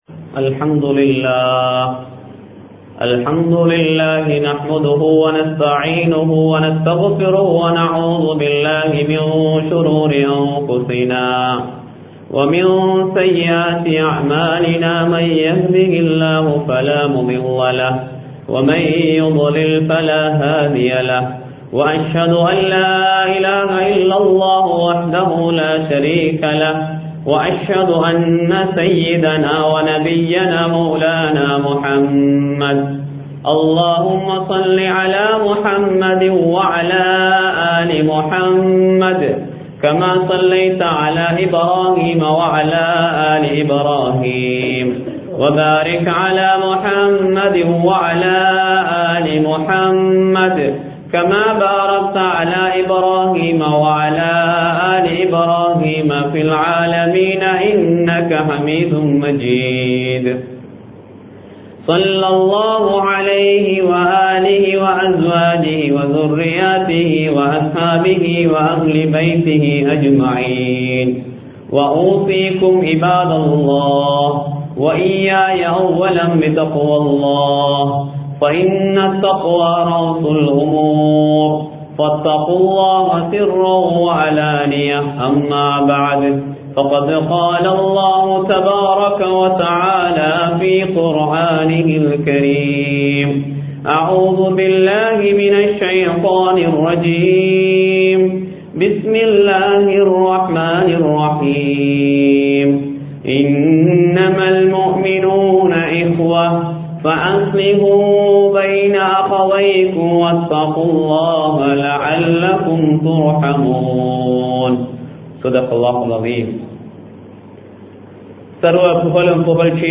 முஹர்ரம் மாதத்தின் சிறப்புகள் (Highlights of the Month Muharram) | Audio Bayans | All Ceylon Muslim Youth Community | Addalaichenai
Muhiyadeen Jumua Masjith